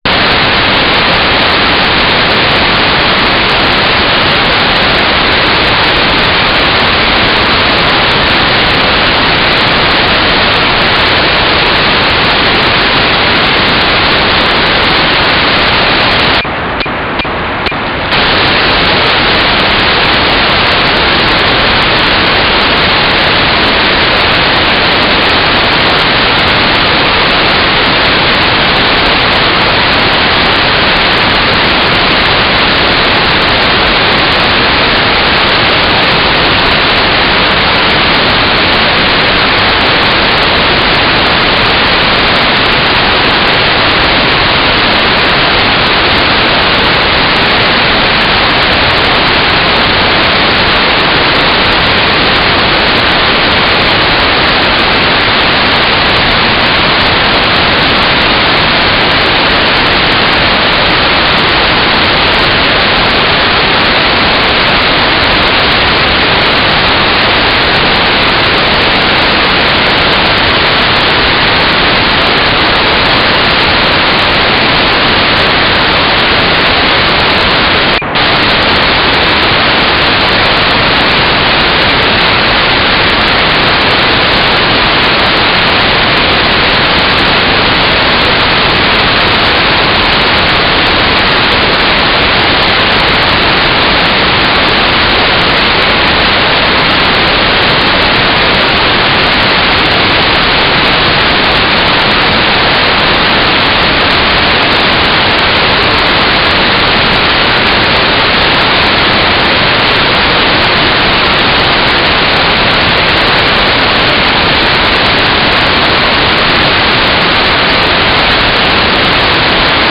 "transmitter_mode": "GMSK",